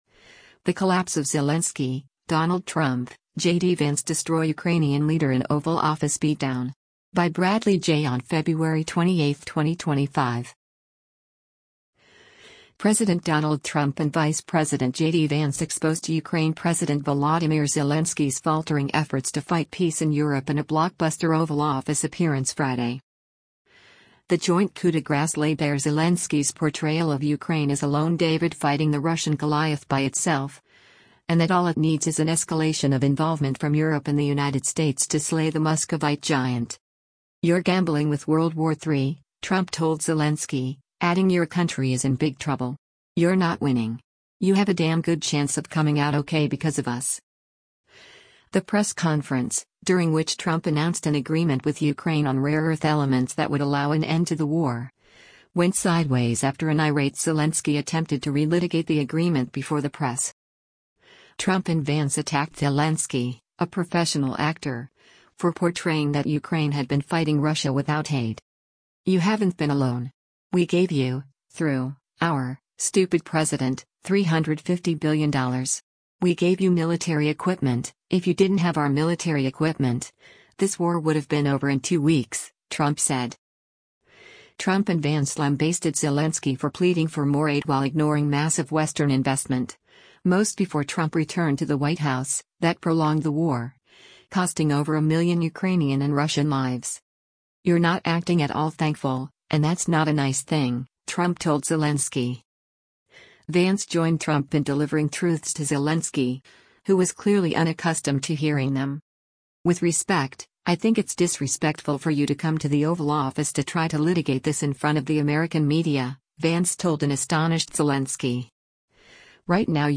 The press conference, during which Trump announced an agreement with Ukraine on rare earth elements that would allow an end to the war, went sideways after an irate Zelensky attempted to relitigate the agreement before the press.
A flustered Zelensky, shaking his head at times, was unprepared to meet criticism after years being feted by the Biden White House and other European leaders.